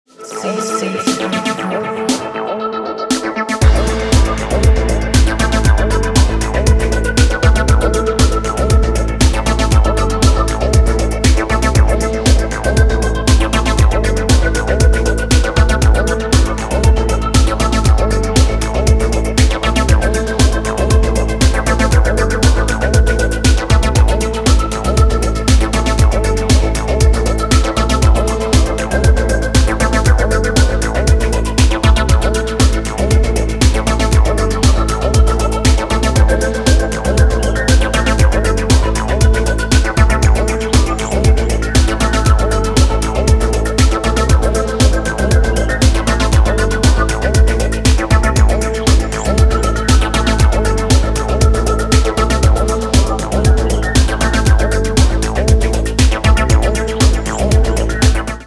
hypnotic electronic rhythms
Electro Techno Acid Trance